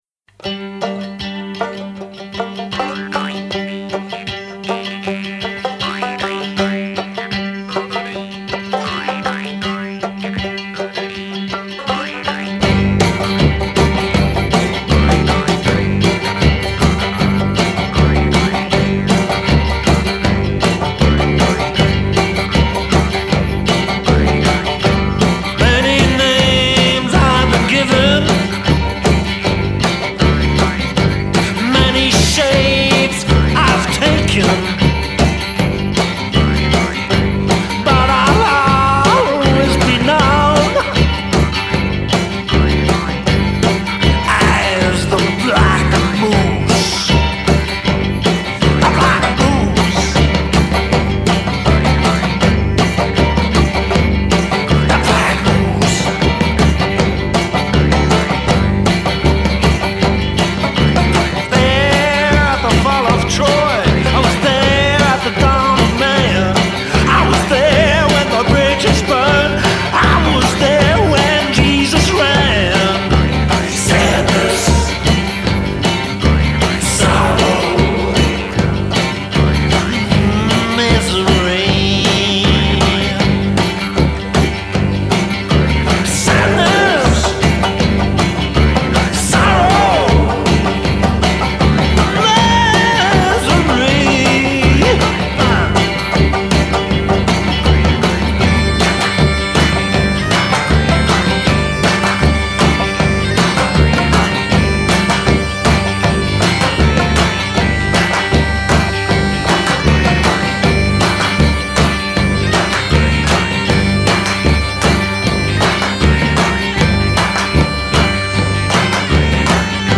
Exotica Dark Country Folk and Deadly Strings
rotten Back pipes